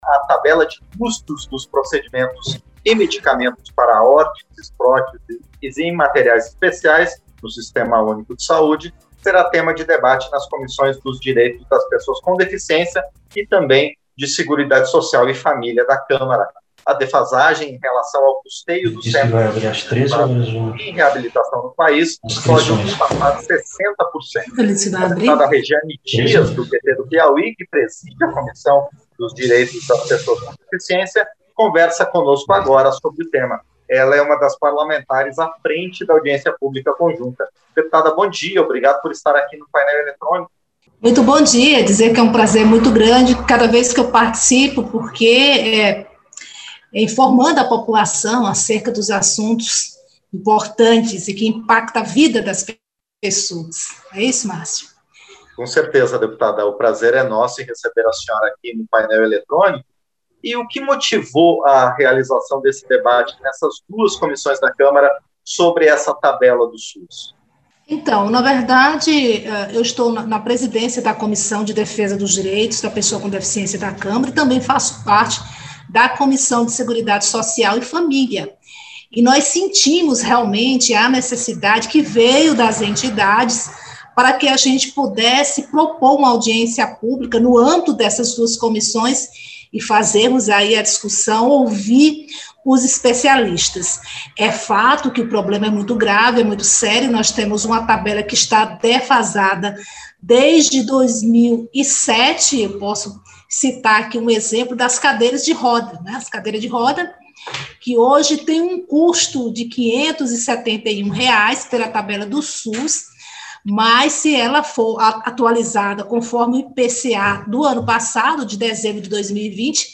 Entrevista - Dep. Rejane Dias (PT-PI)